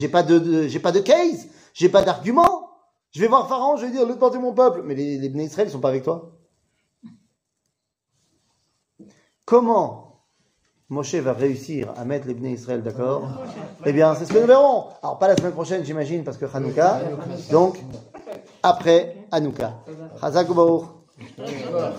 שיעור מ 06 דצמבר 2023